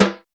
PERC.97.NEPT.wav